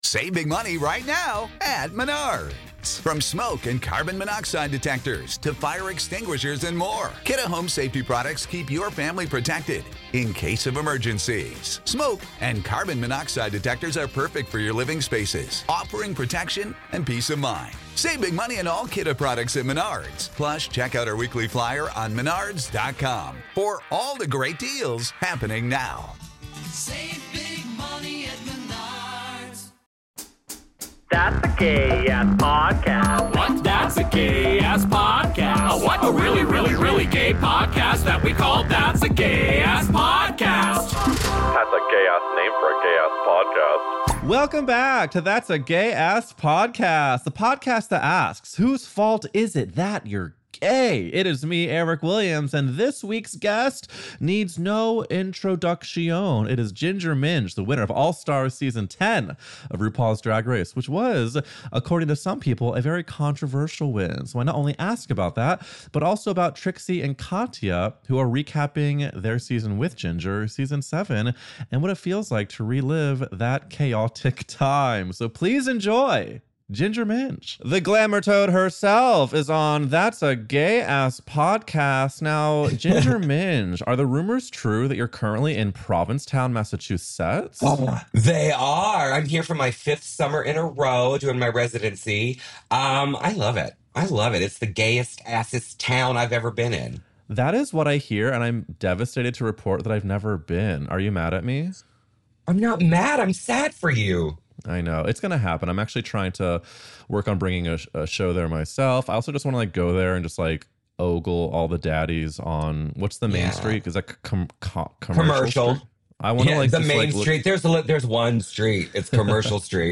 In a candid talk, Glenn and Jonathan discuss preventing another Patriot Act, free speech threats from the activist Left, and whether Trump has crossed a “red line.”